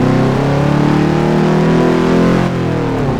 Index of /server/sound/vehicles/lwcars/dodge_daytona